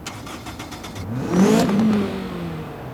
Index of /server/sound/vehicles/lwcars/delta
startup.wav